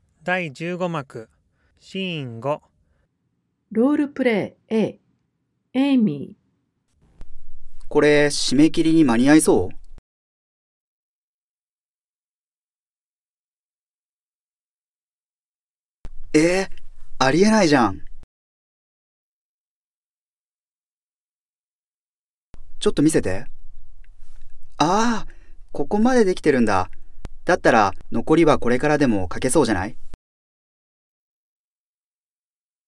Now15-5-SL_3-RolePlay_a.mp3